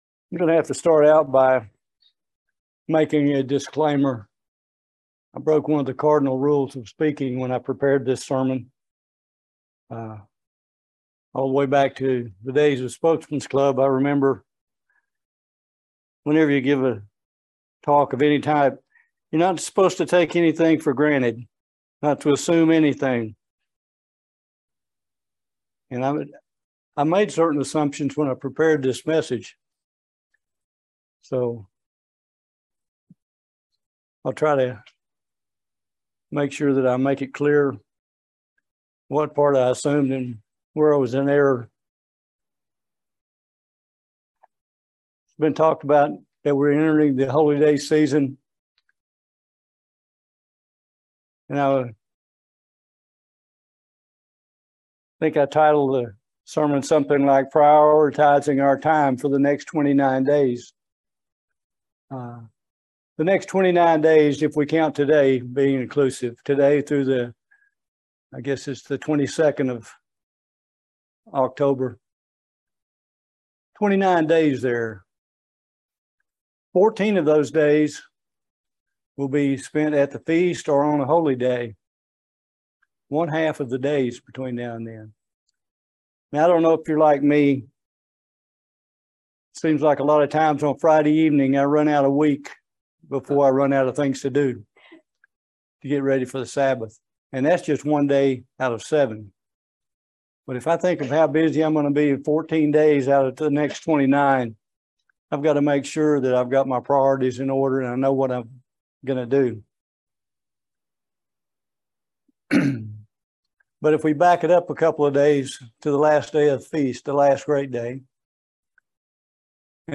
This sermon discusses the importance of continuously communicating with God. This can be done by being consistent in daily Bible Study, Prayer, and Meditation.
Given in Lexington, KY